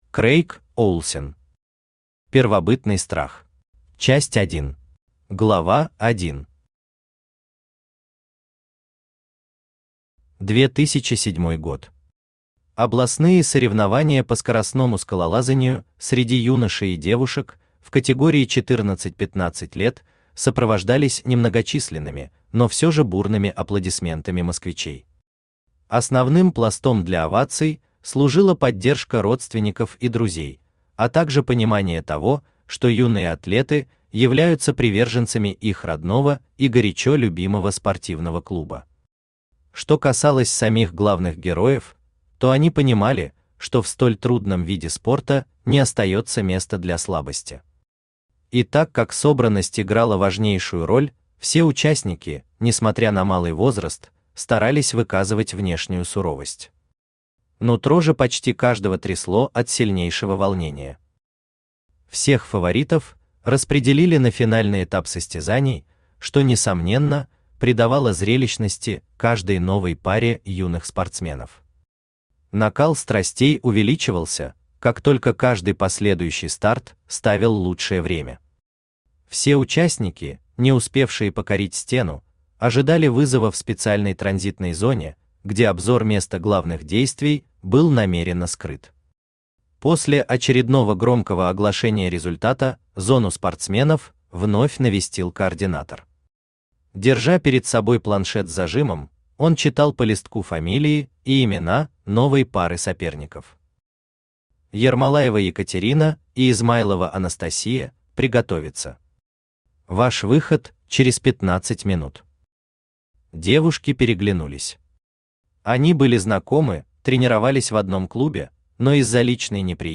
Аудиокнига Первобытный страх | Библиотека аудиокниг
Aудиокнига Первобытный страх Автор Крейг Оулсен Читает аудиокнигу Авточтец ЛитРес.